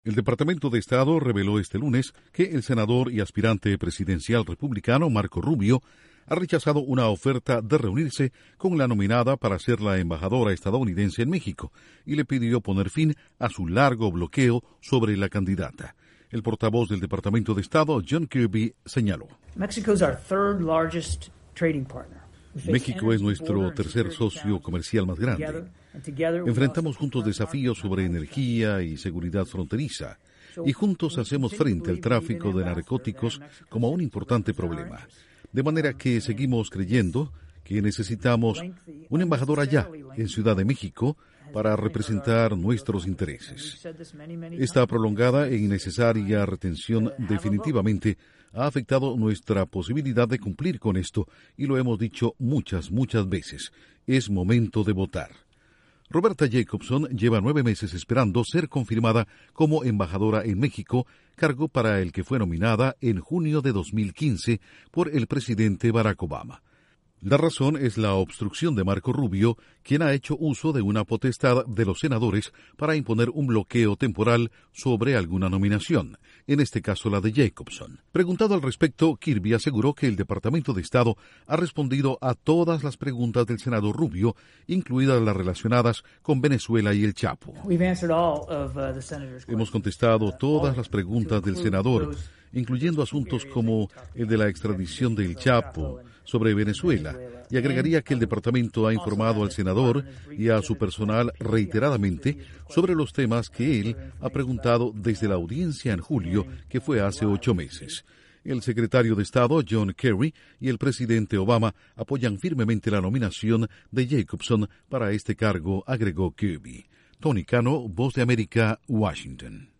Gobierno de Estados Unidos pide al precandidato presidencial republicano, Marco Rubio, detener el bloqueo para la nominada como embajadora en México. Informa desde la Voz de América en Washington